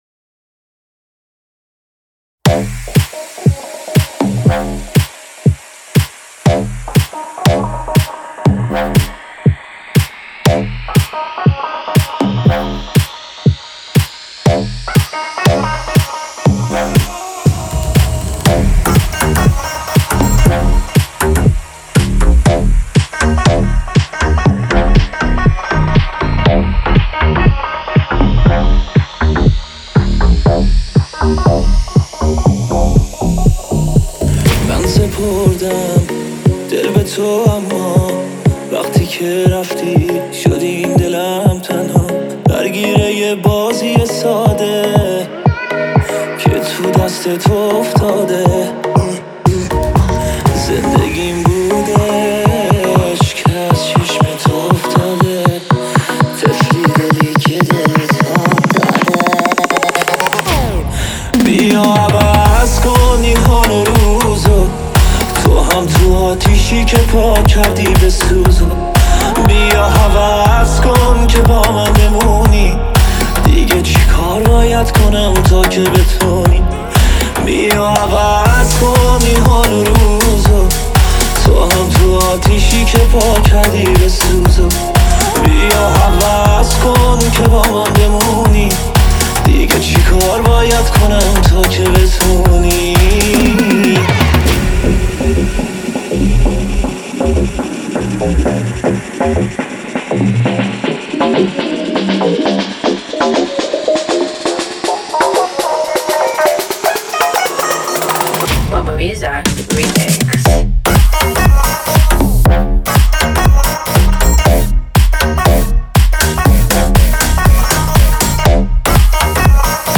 دانلود ریمیکس همین موزیک